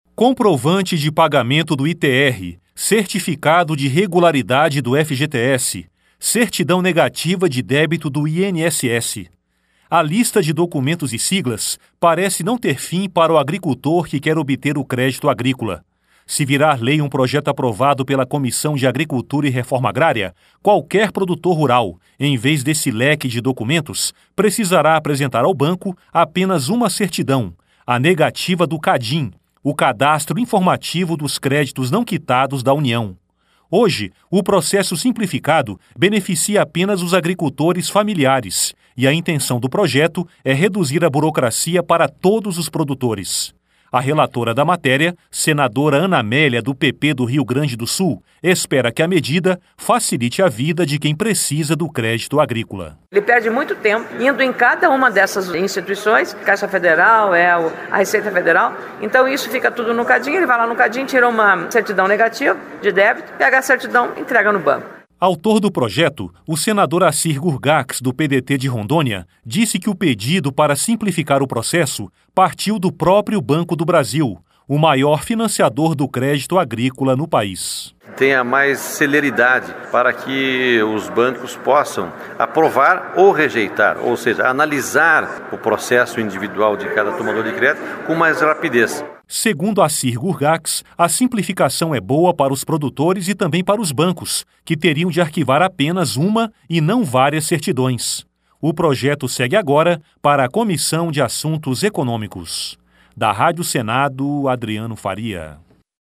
Senadora Ana Amélia
Senador Acir Gurgacz